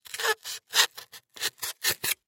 Звуки пенопласта
Звук разрезания пенопласта ножом